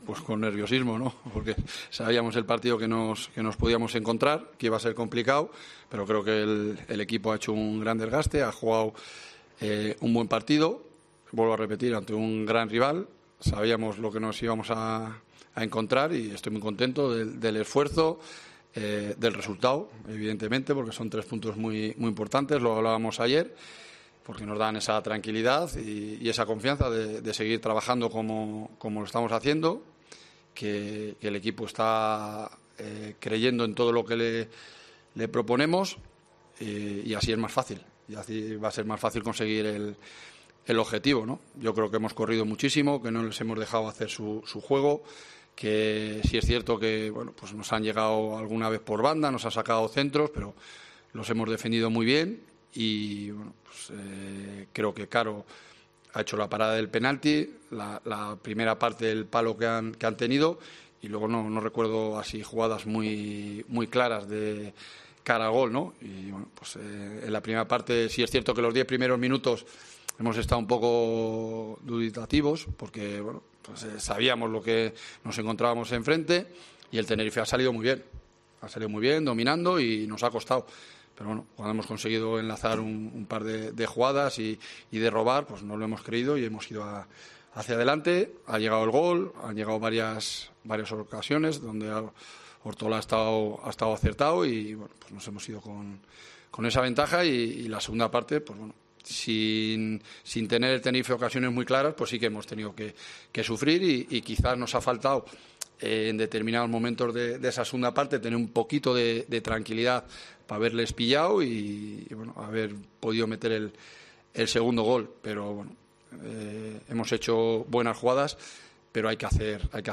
Escucha y lee aquí las palabras del entrenador de la Deportiva Ponferradina tras la victoria 1-0 en El Toralín ante el CD Tenerife